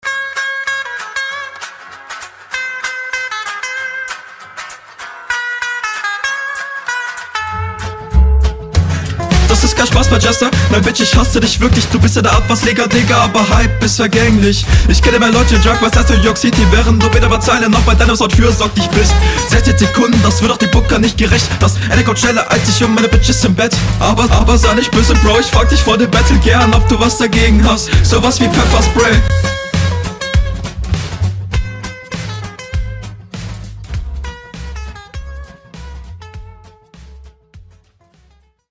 Driveby Runde